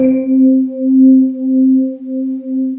Index of /90_sSampleCDs/USB Soundscan vol.28 - Choir Acoustic & Synth [AKAI] 1CD/Partition D/08-SWEEPOR